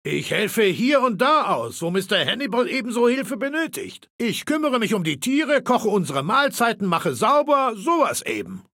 Datei:Maleold01 ms06 ms06whatdoyoudo 0005a217.ogg
Fallout 3: Audiodialoge